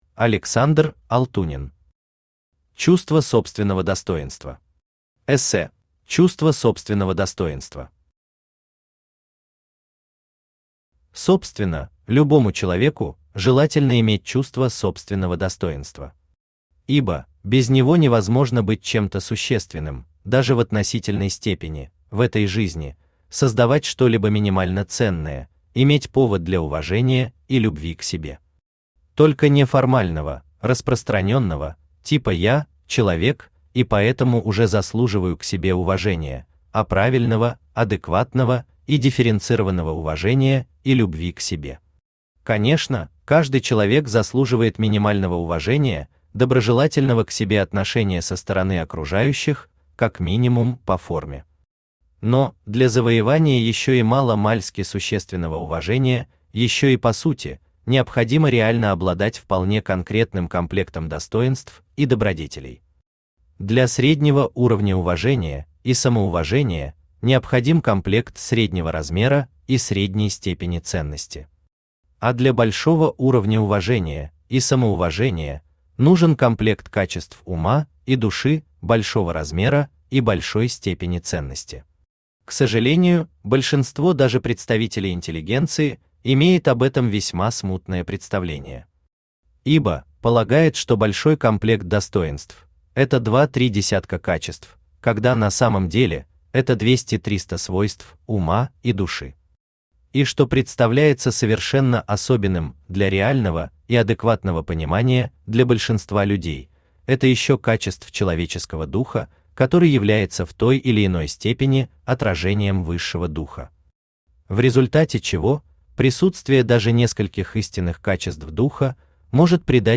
Аудиокнига Чувство собственного достоинства. Эссе | Библиотека аудиокниг
Aудиокнига Чувство собственного достоинства. Эссе Автор Александр Иванович Алтунин Читает аудиокнигу Авточтец ЛитРес.